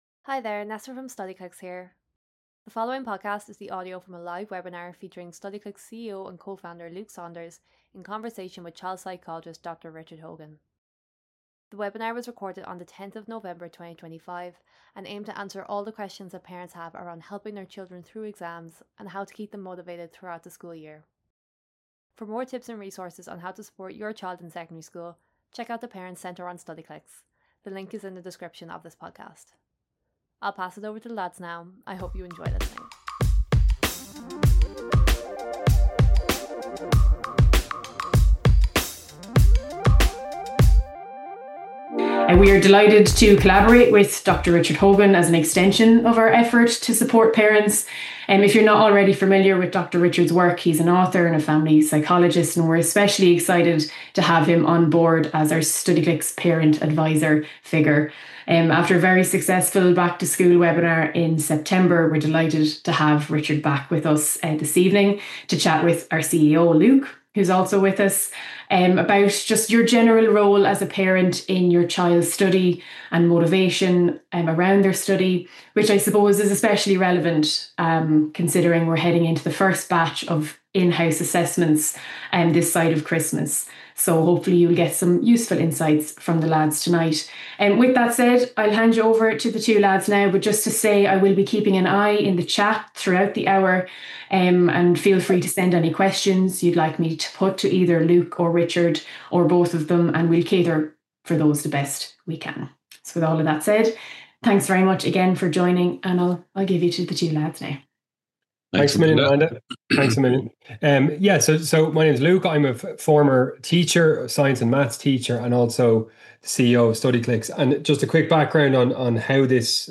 we hosted a live webinar
This is the audio recording from that webinar.